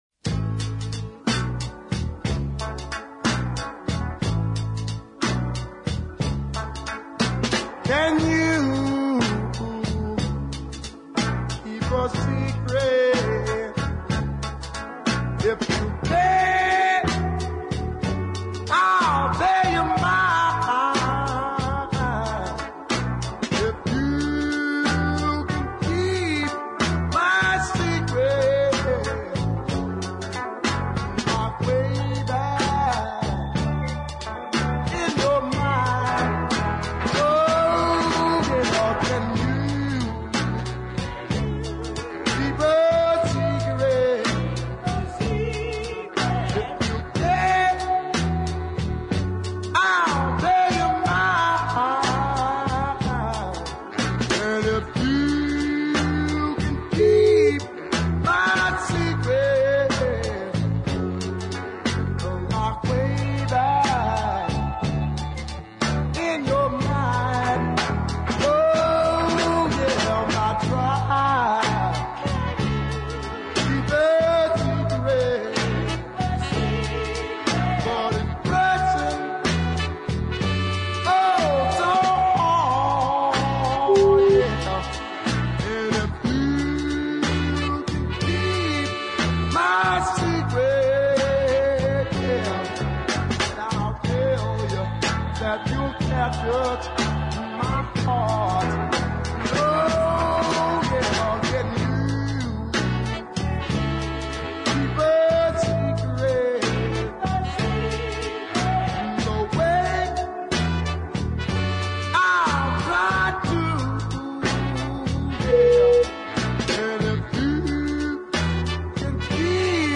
strong deep ballads